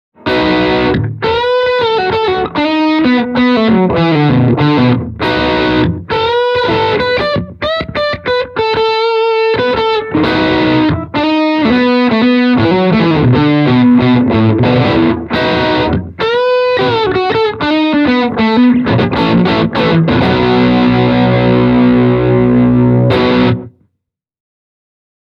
Ääniesimerkit on äänitetty Zoom H1 -tallentimilla.
Kaikki kolme ääniesimerkkiä on soitettu Les Paul -tyylisellä kitaralla:
Yamaha THR10C – Class A + Chorus + Tape Echo